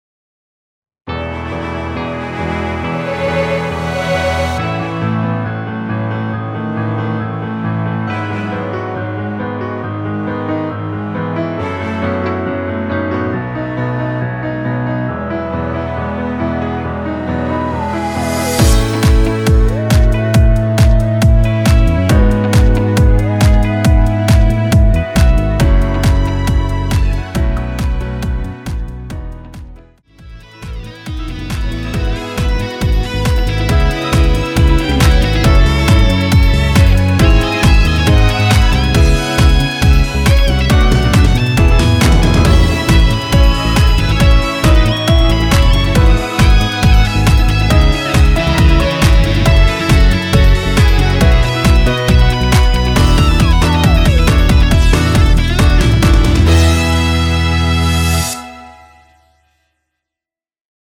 엔딩이 페이드 아웃이라?노래 하시기 좋게 엔딩을 만들어 놓았으니 미리듣기 참조 하세요.
앞부분30초, 뒷부분30초씩 편집해서 올려 드리고 있습니다.
중간에 음이 끈어지고 다시 나오는 이유는